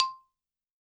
52-prc10-bala-c4.wav